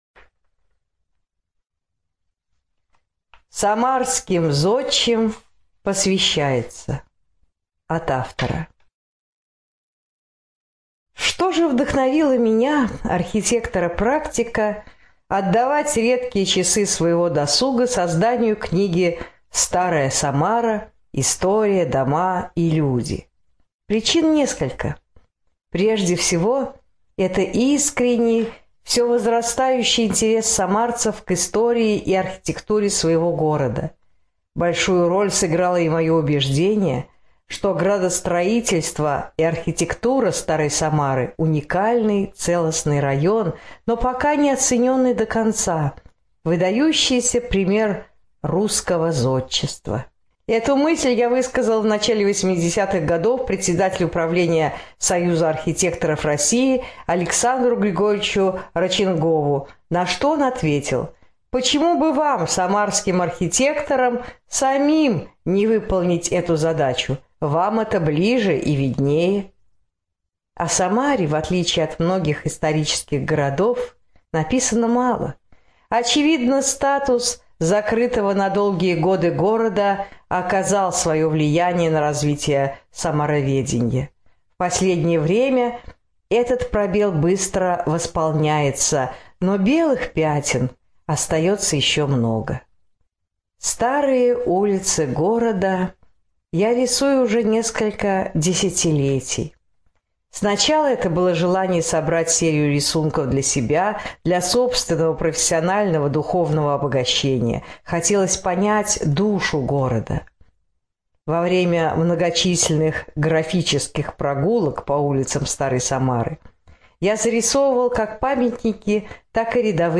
Студия звукозаписиСамарская областная библиотека для слепых